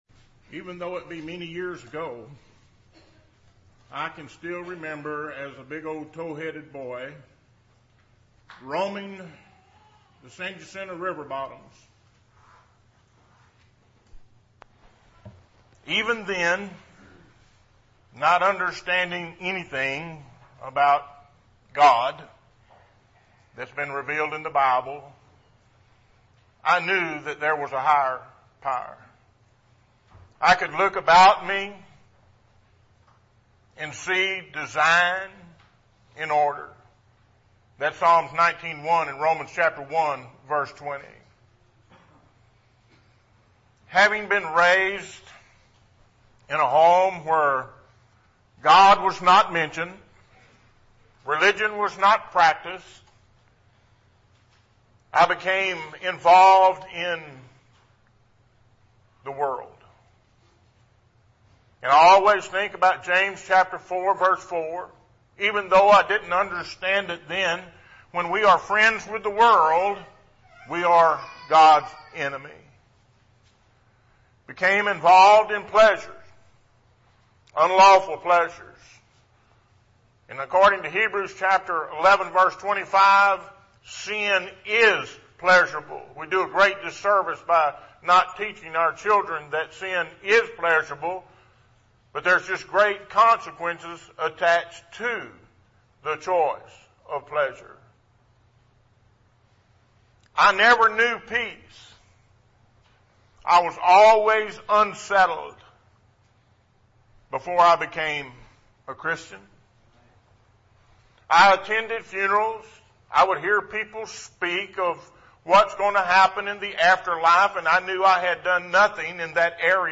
Series: Sermon